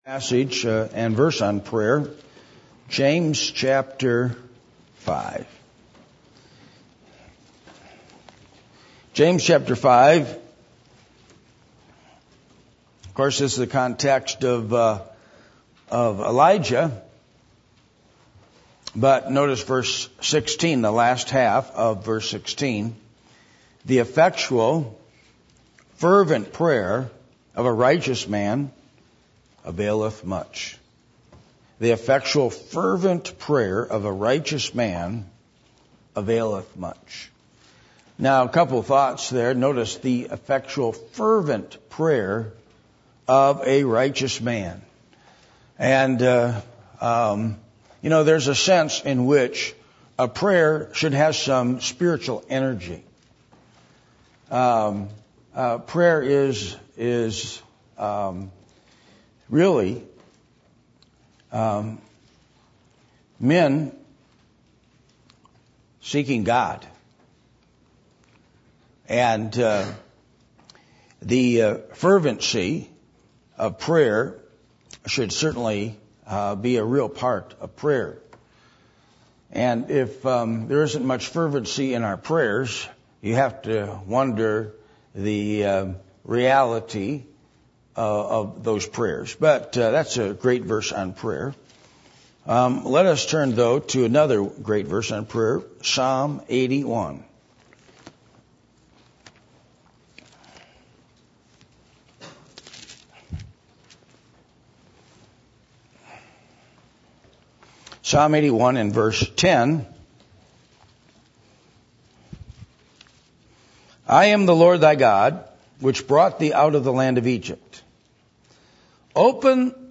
Passage: James 5:16 Service Type: Midweek Meeting %todo_render% « What Is Spirituality?